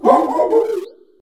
Cri de Toutombe dans Pokémon HOME.